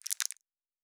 Plastic Foley 12.wav